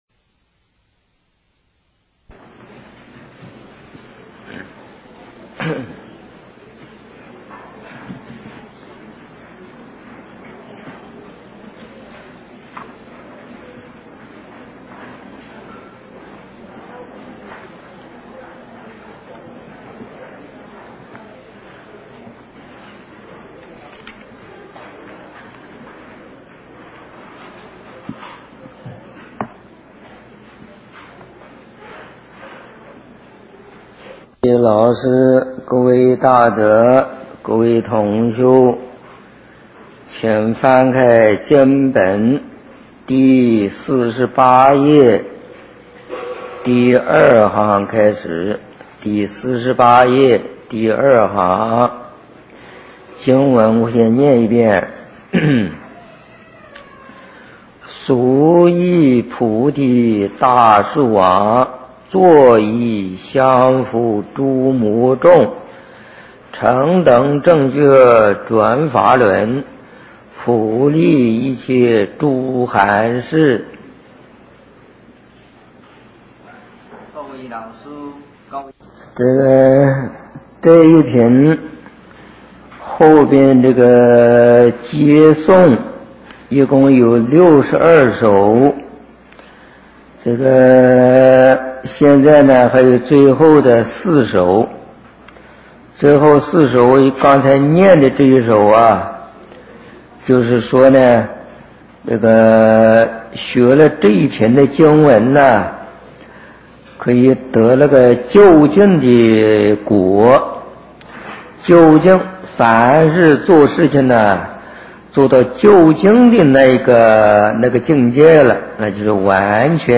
佛學講座-聲音檔